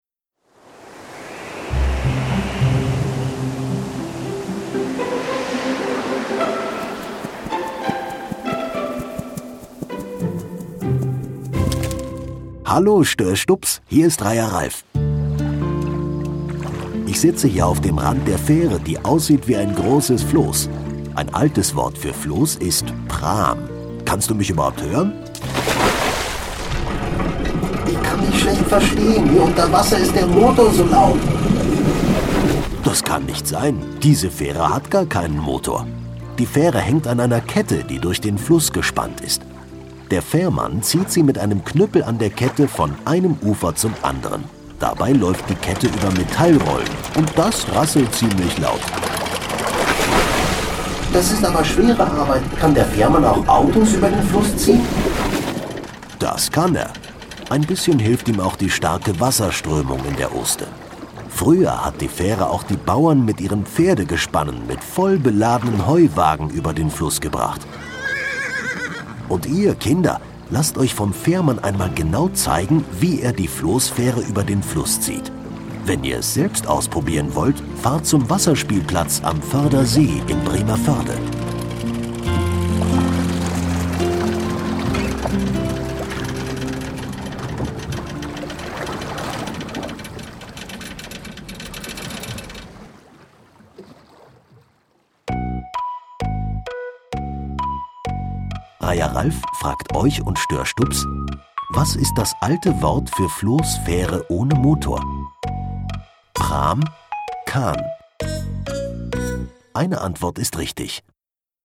Gräpel Prahmfähre - Kinder-Audio-Guide Oste-Natur-Navi